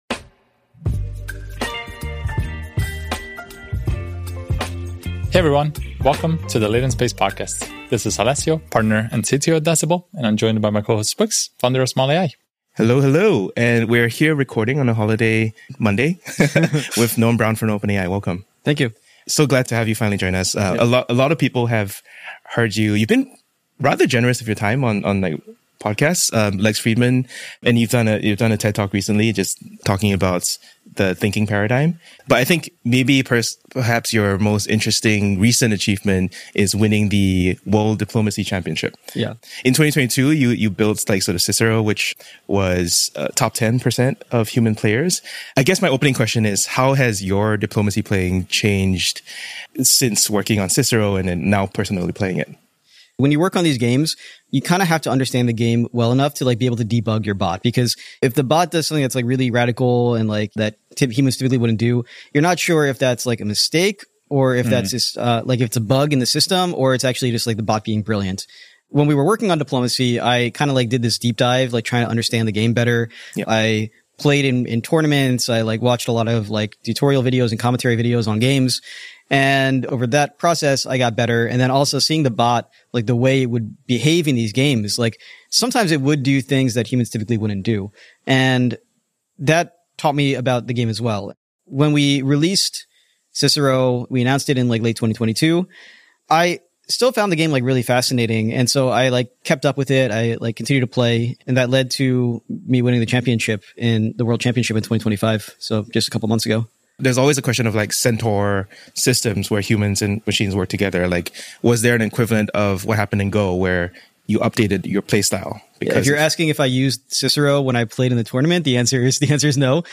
public ios_share Latent Space: The AI Engineer Podcast chevron_right Scaling Test Time Compute to Multi-Agent Civilizations — Noam Brown, OpenAI whatshot 1185 snips Jun 19, 2025 Guest Noam Brown Noam Brown, who leads the multi-agent team at OpenAI, shares insights from his groundbreaking work in AI, especially in competitive strategy games like poker and Diplomacy. He discusses the fascinating impact of AI on human gameplay and critiques the constraints of the System 1/2 thinking model in AI reasoning.